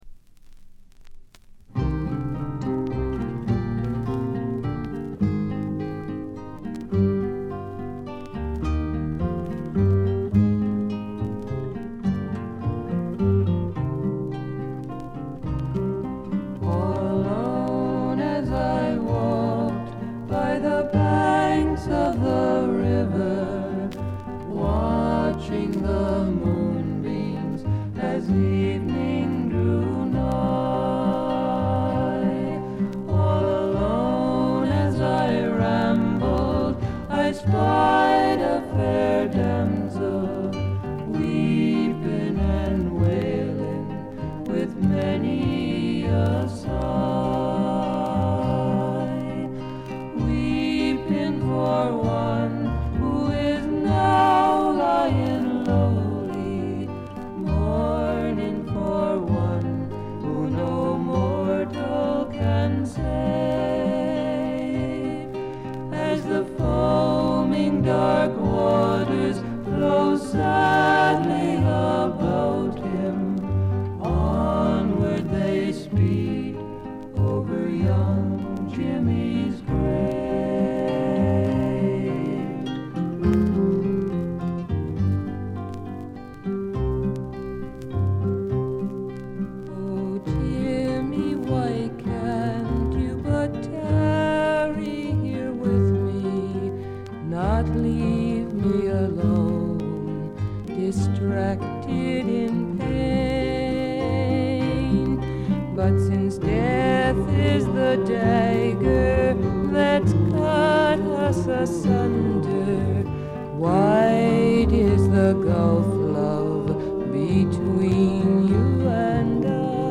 ホーム > レコード：米国 SSW / フォーク
低いバックグラウンドノイズは常時出ていますが、特に目立つノイズはありません。
試聴曲は現品からの取り込み音源です。